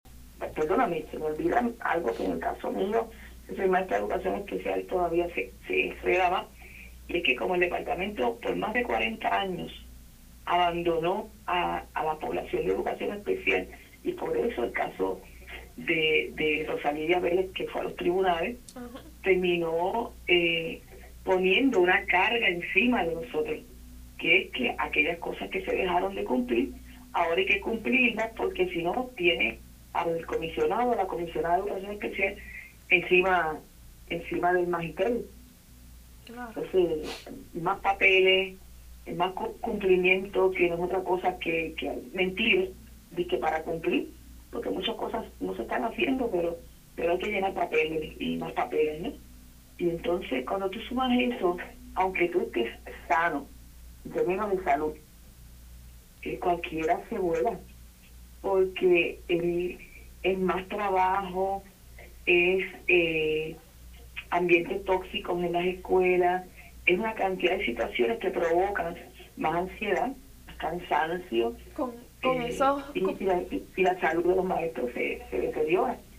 en entrevista con Radio Isla